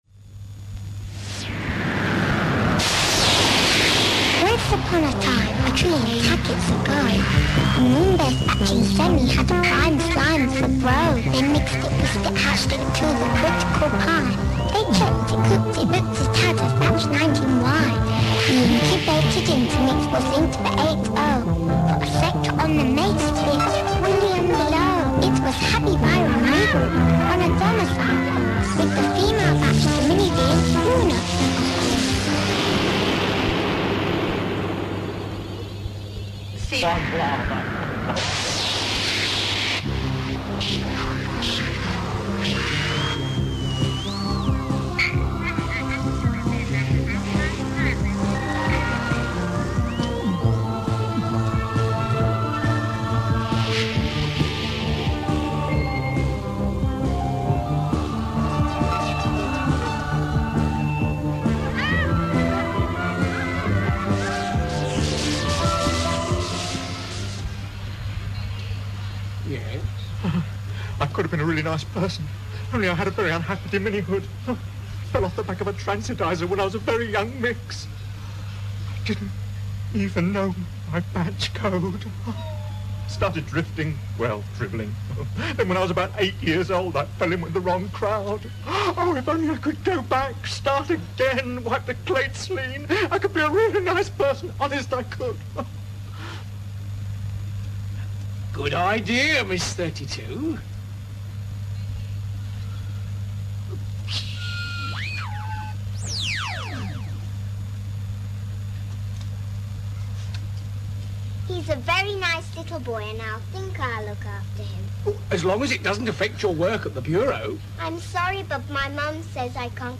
opening theme